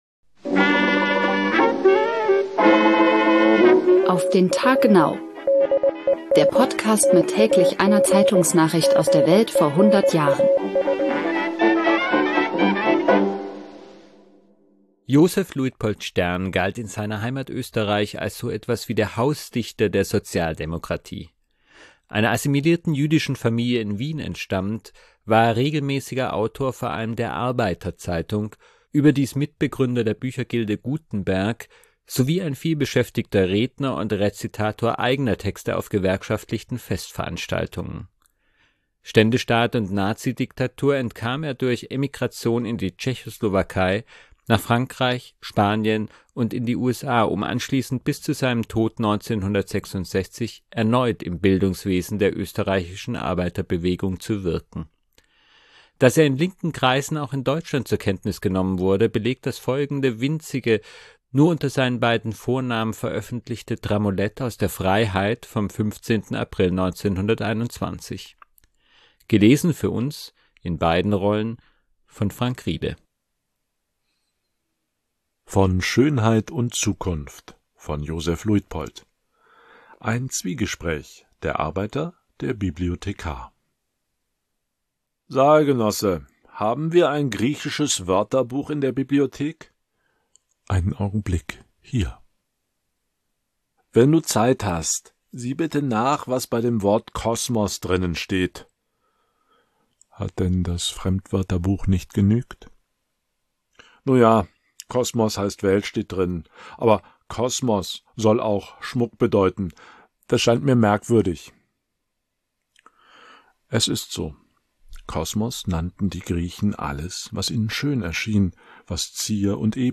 Gelesen für uns, in
beiden Rollen,